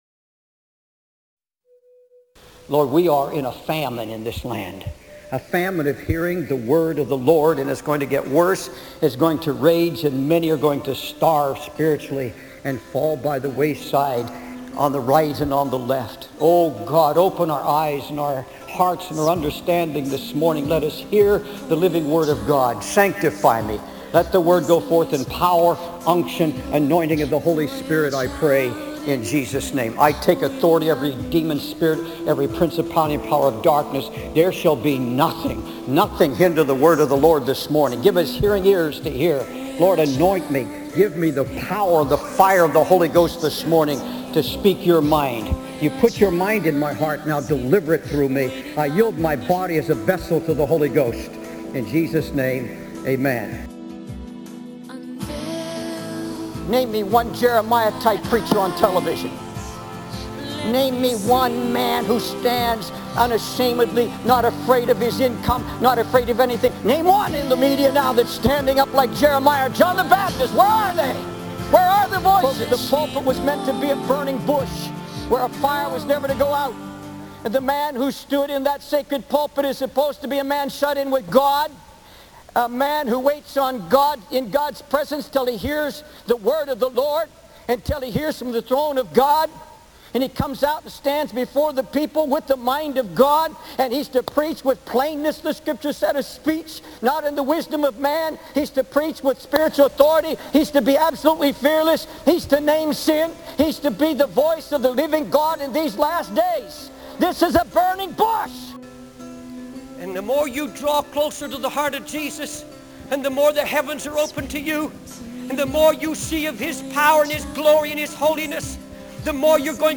In this sermon, the preacher laments the lack of true Jeremiah-type preachers in the media who fearlessly proclaim the word of God.